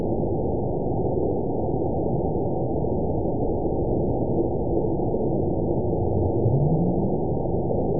event 920512 date 03/28/24 time 15:25:05 GMT (1 month ago) score 9.52 location TSS-AB02 detected by nrw target species NRW annotations +NRW Spectrogram: Frequency (kHz) vs. Time (s) audio not available .wav